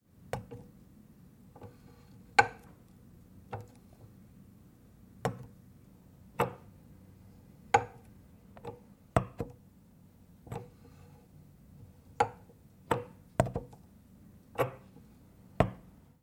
描述：1916年Victor Victrola VVXI留声机。 将针臂的各种取出降低到记录上。当针放入其静止位置时，会发生更多的金属咔哒声。 麦克风的位置在机器上方指向转盘。 用Sennheiser MKH416录制成Zoom F8录音机。